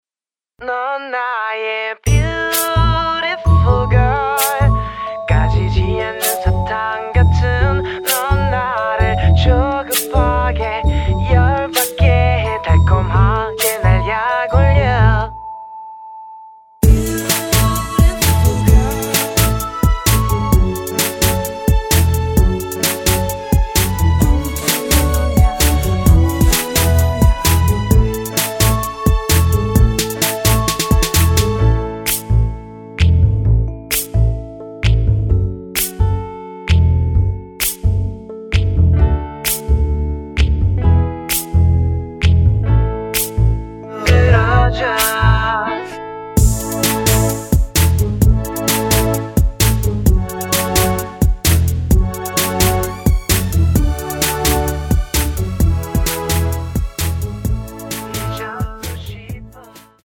앞부분30초, 뒷부분30초씩 편집해서 올려 드리고 있습니다.
원곡의 보컬 목소리를 MR에 약하게 넣어서 제작한 MR이며
노래 부르 시는 분의 목소리가 크게 들리며 원곡의 목소리는 코러스 처럼 약하게 들리게 됩니다.